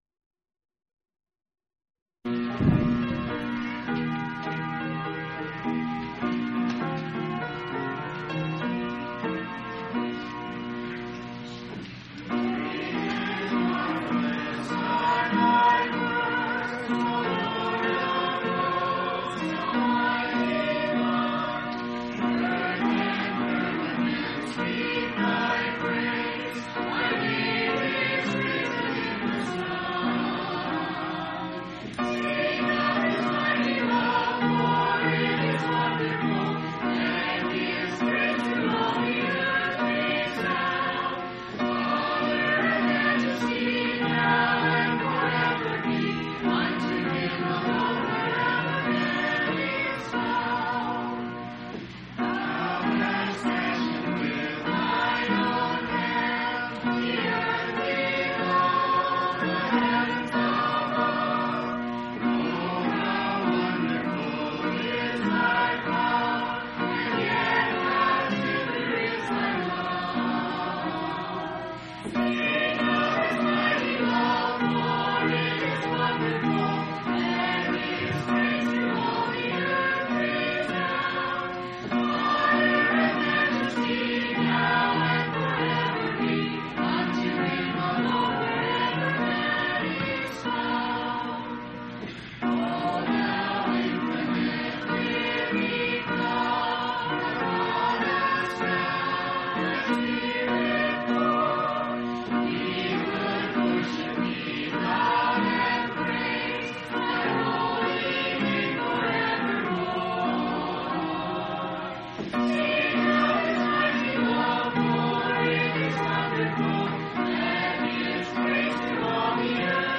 8/29/1993 Location: Phoenix Local Event